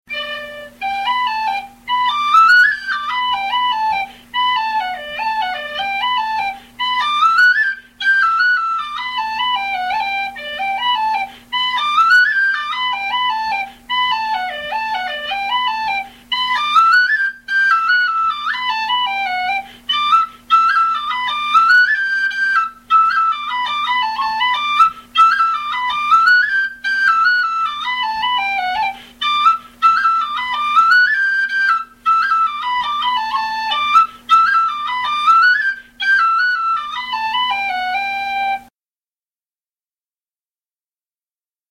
Saddle the Pony jig G pdf 22KB txt